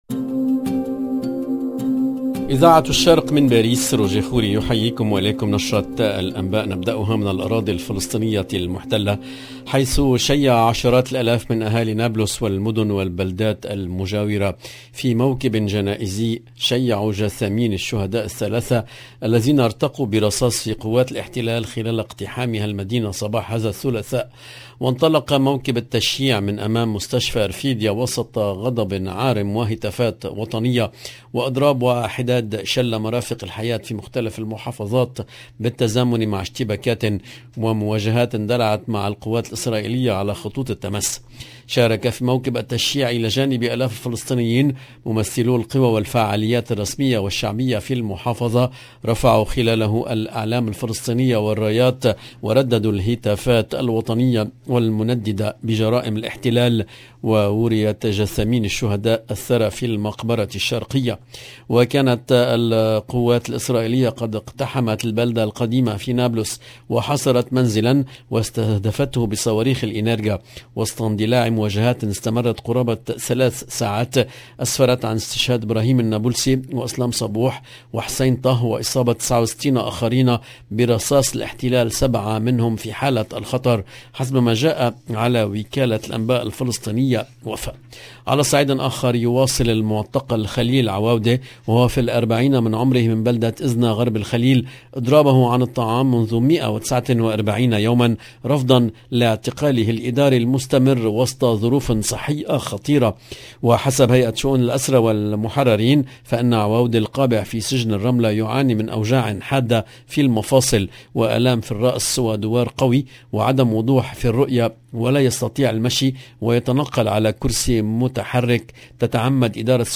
LE JOURNAL DU SOIR EN LANGUE ARABE DU 9/08/22